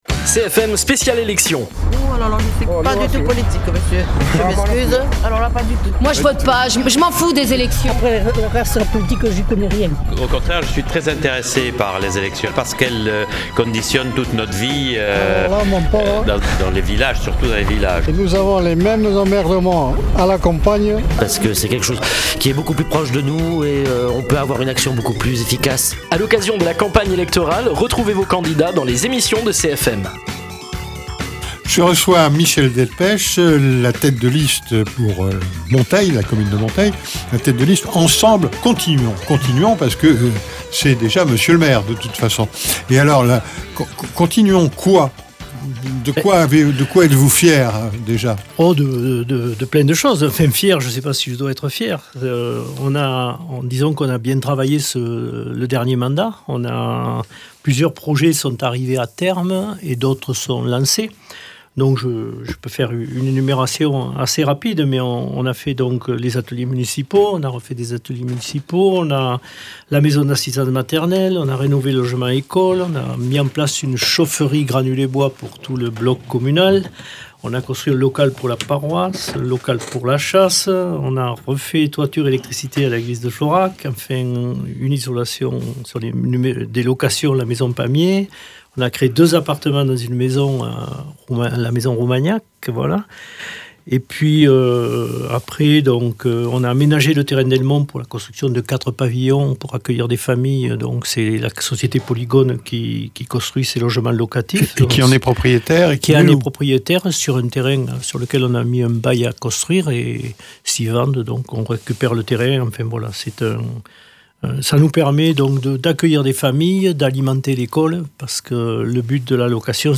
Invité(s) : Michel Delpech, pour la liste ensemble continuons